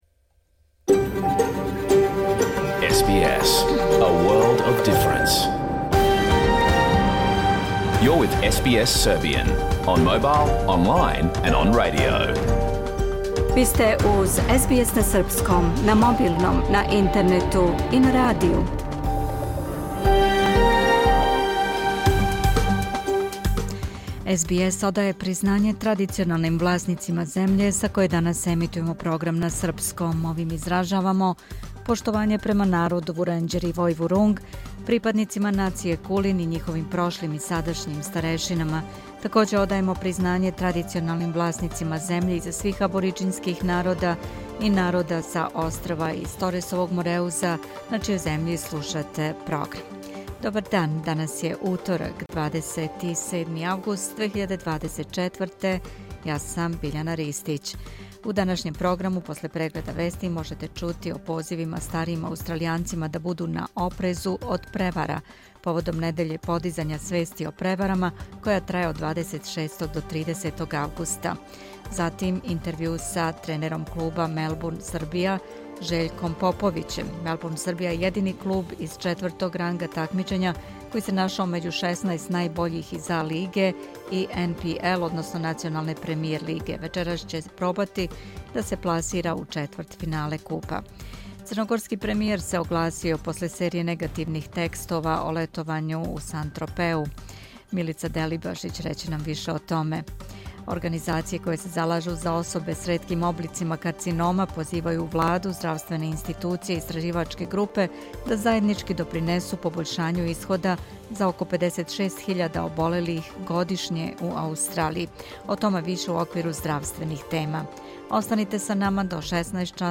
Програм емитован уживо 27. августа 2024. године
Уколико сте пропустили данашњу емисију, можете је послушати у целини као подкаст, без реклама.